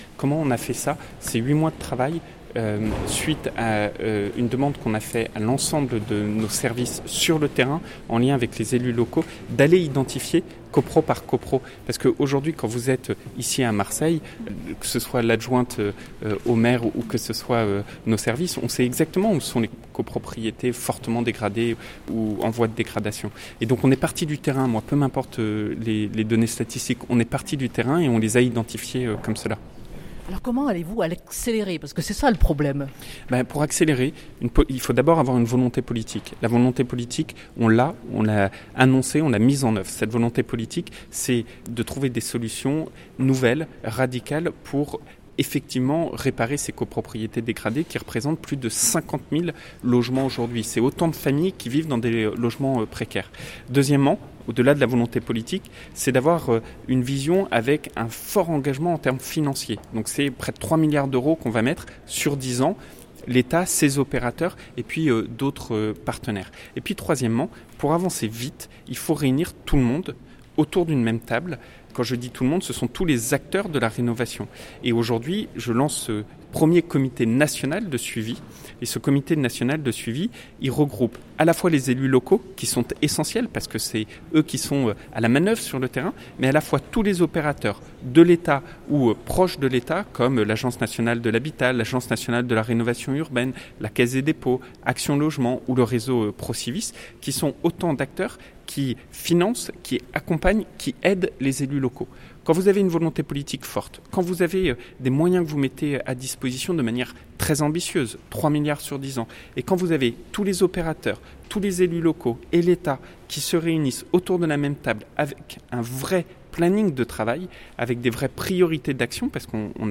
Entretien.
julien_denormandie_itw_micro_tendu_10_10_18.mp3